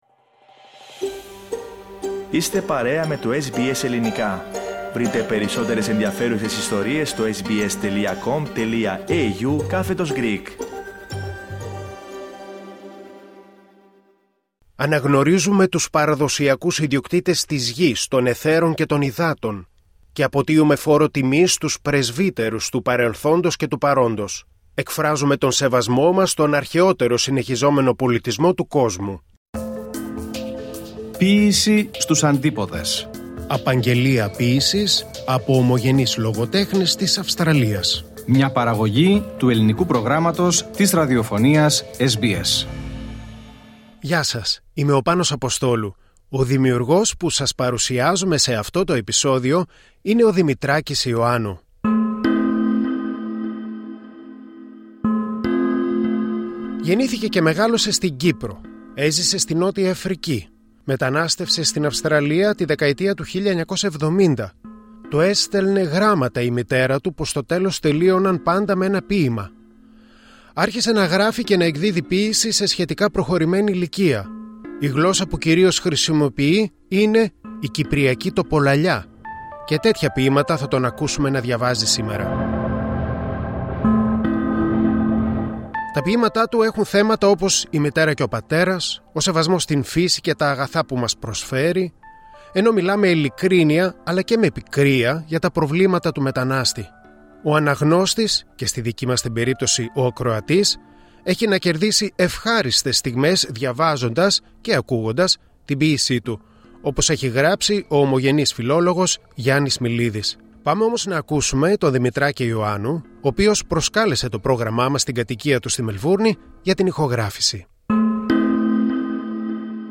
Του ζητήσαμε να κάνει τις απαγγελίες στην κυπριακή διάλεκτο.